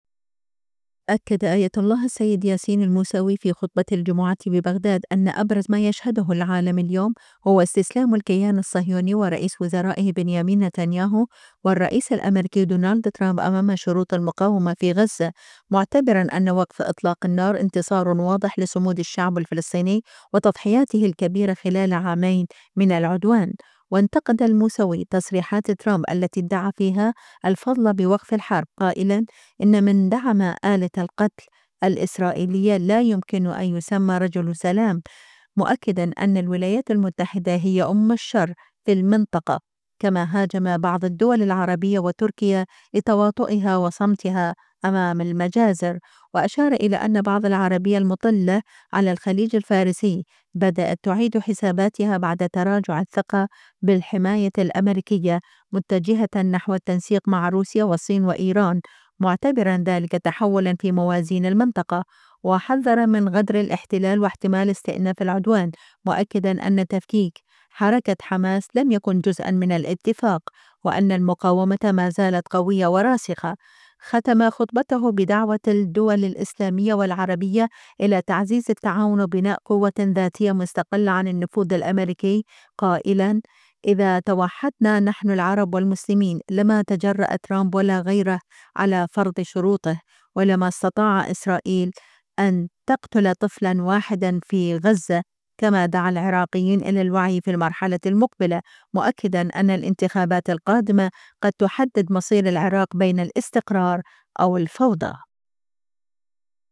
إمام جمعة بغداد: المقاومة فرضت شروطها ووقف النار انتصار للفلسطينيين
أكّد آية الله السيد ياسين الموسوي في خطبة الجمعة ببغداد أن أبرز ما يشهده العالم اليوم هو استسلام الكيان الصهيوني ورئيس وزرائه بنيامين نتنياهو والرئيس الأميركي دونالد ترامب أمام شروط المقاومة في غزة، معتبراً أن وقف إطلاق النار انتصار واضح لصمود الشعب الفلسطيني وتضحياته الكبيرة خلال عامين من العدوان.